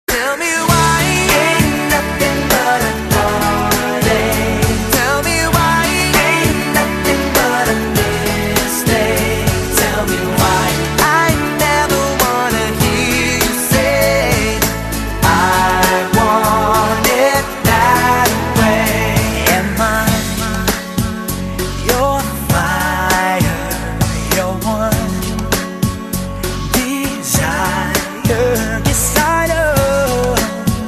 摇滚下载